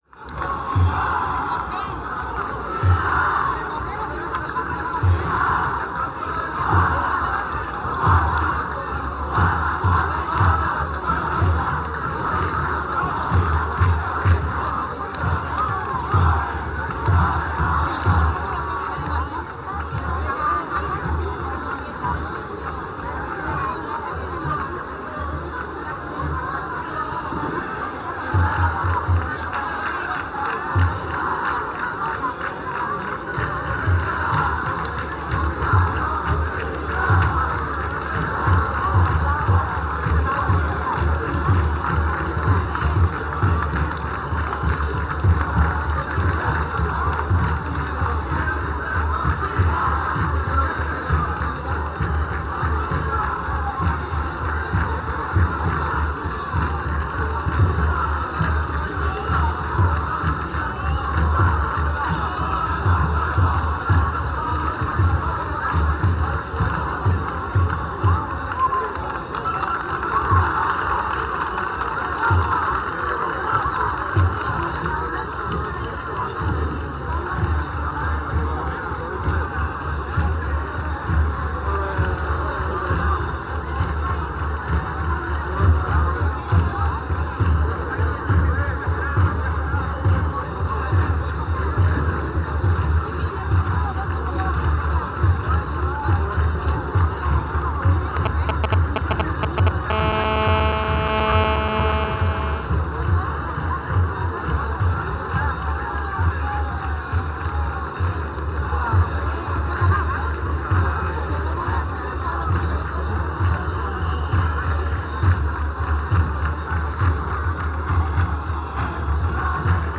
Es lo que hicimos el grupo de antropólogos de Tarragona en la Romería del Rocío de 1980.
Junto a la cinematografía etnogràfica registramos el ambiente sonoro mediante un «cassete Philips», para emplearlo como pista de sonido en el documento visual. La calidad resultante era modesta. Además al transcribirla a la pista de un mm de la banda magnética de la película de super8 la calidad empeoraba.